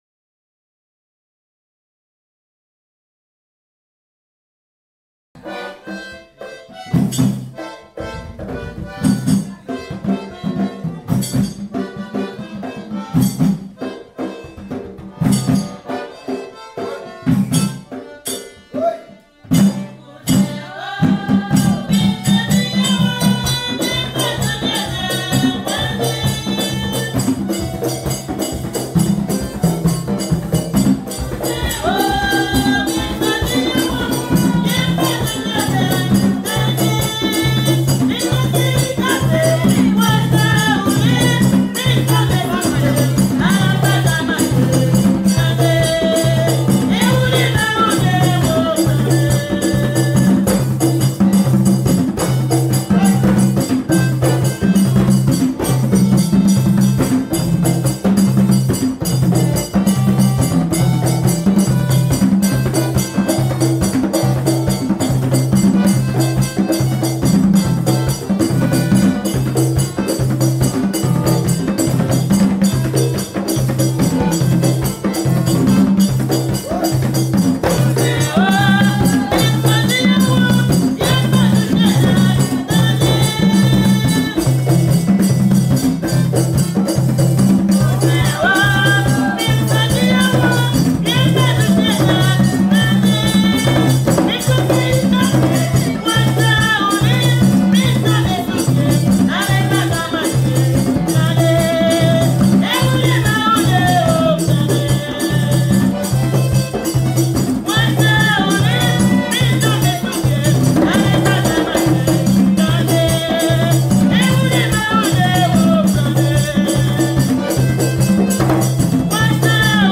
Tambours d’ayiti
2022 CONTRE DANSE (VIEUX GRIS) FLOKLORE HAITIEN audio closed https
Tambours-d-Haiti.mp3